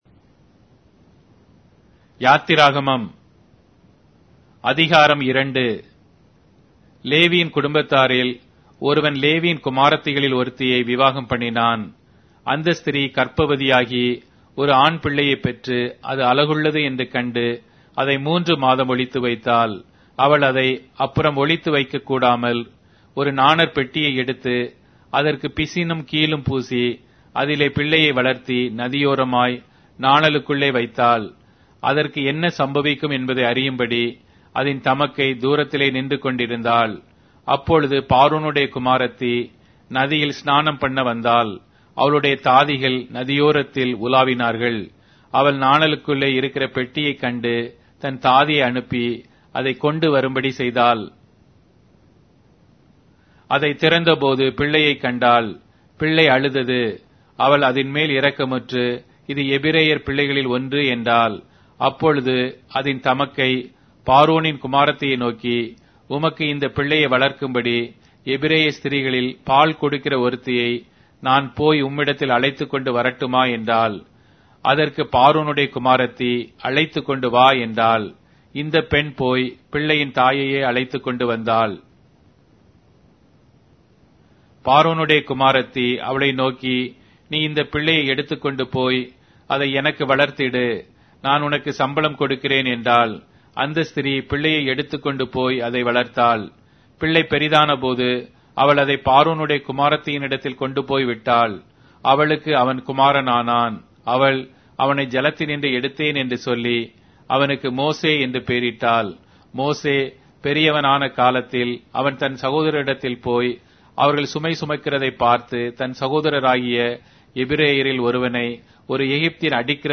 Tamil Audio Bible - Exodus 34 in Ocvkn bible version